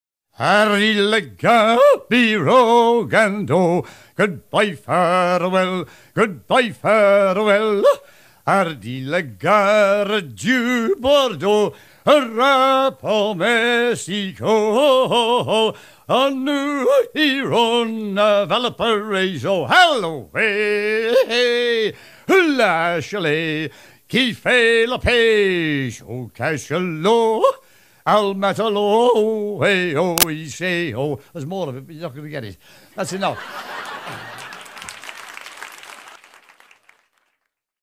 au musée de Mystic Seaport, aux Etats-Unis d'Amérique
à virer au cabestan
Chansons maritimes